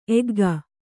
♪ egga